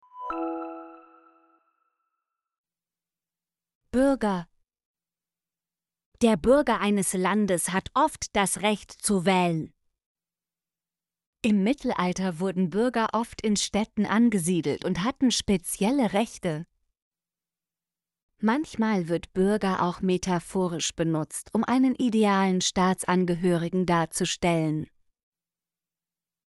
bürger - Example Sentences & Pronunciation, German Frequency List